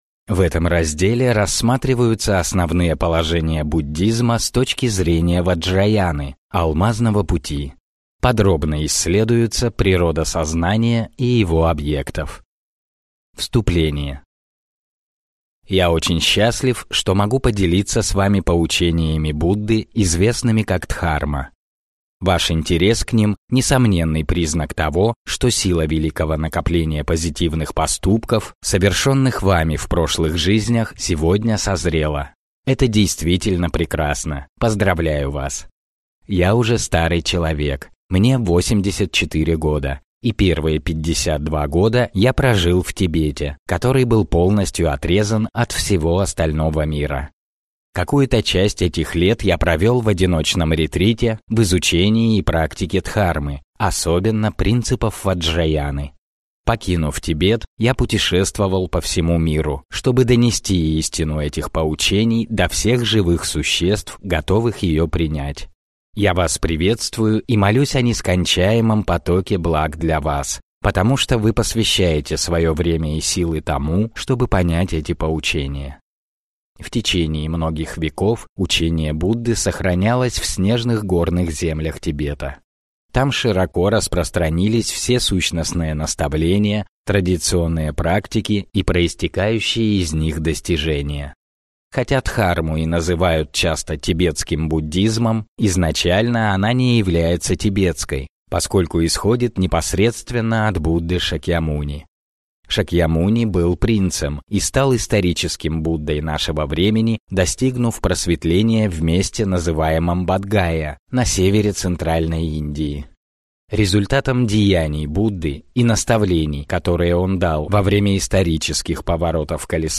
Аудиокнига Практический буддизм. Том V. Простые шаги к глубокому опыту | Библиотека аудиокниг